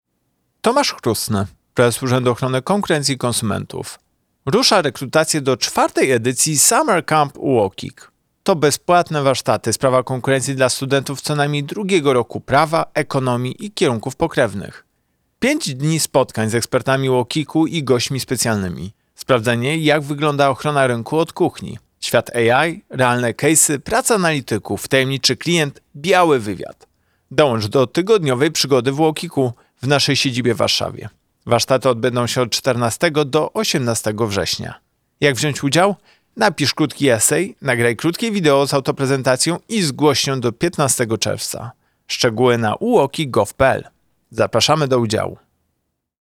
Wypowiedź Prezesa UOKiK Tomasza Chróstnego